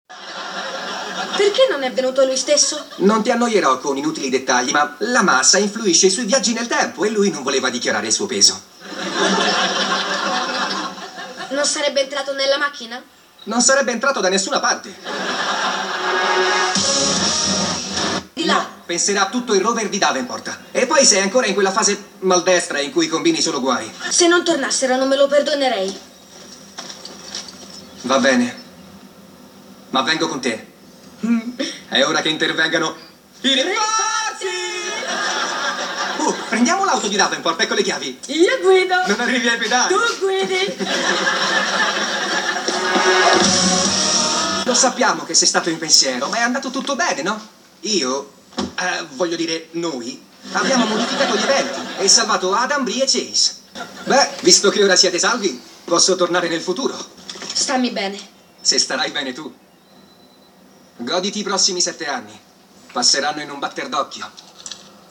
nel telefilm "Lab Rats", in cui doppia il Leo del futuro.